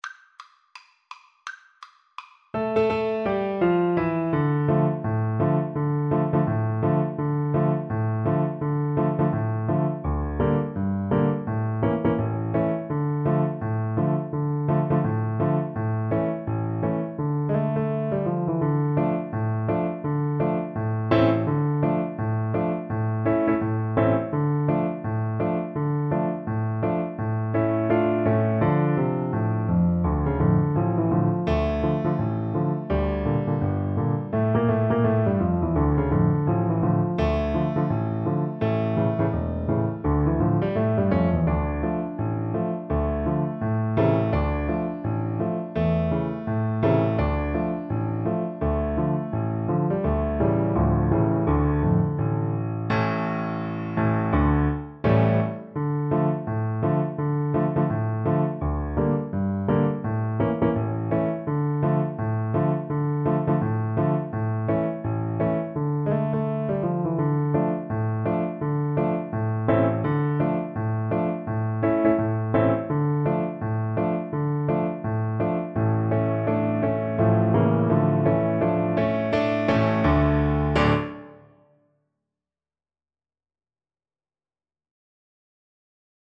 Quick Swing = 84
D minor (Sounding Pitch) (View more D minor Music for Piano Duet )